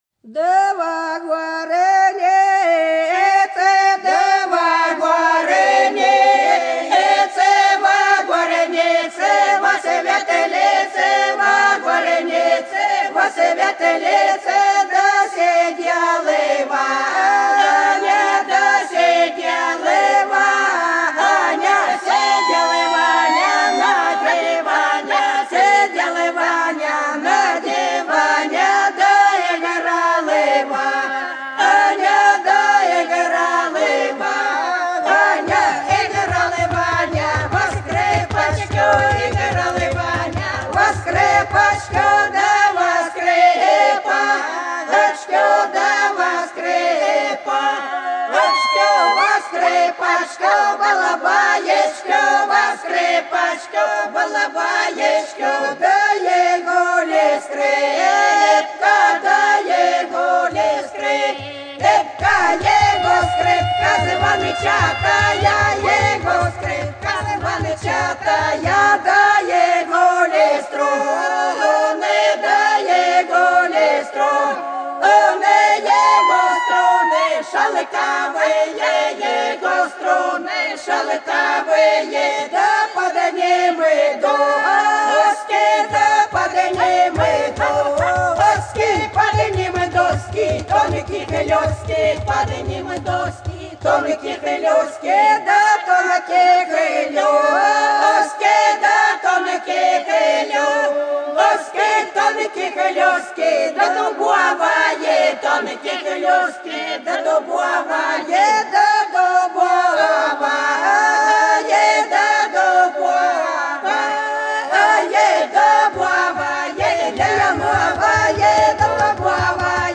Хороша наша деревня Да во горнице, во светлице - плясовая (с. Иловка)
01_Да_во_горнице,_во_светлице_-_плясовая.mp3